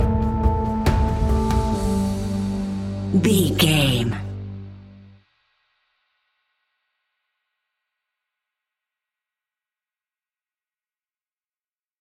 Aeolian/Minor
ominous
dark
haunting
eerie
electric piano
synthesiser
drums
horror music